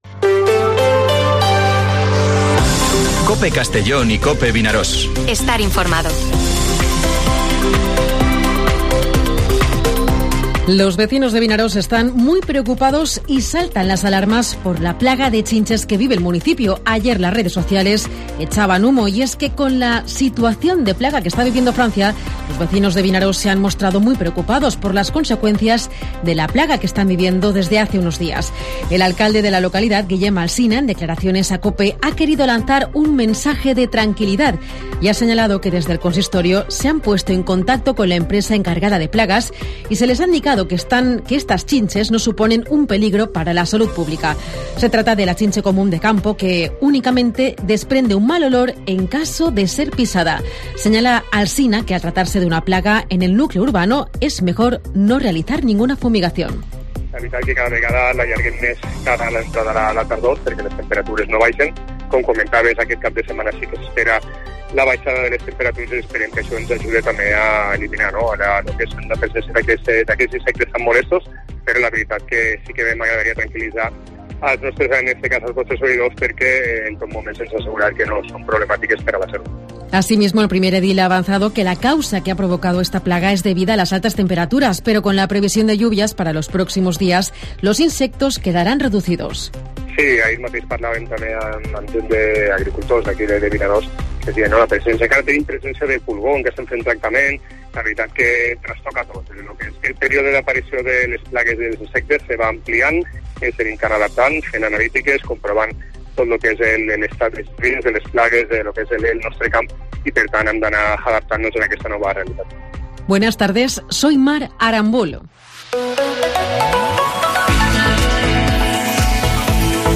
Informativo Herrera en COPE en la provincia de Castellón (17/10/2023)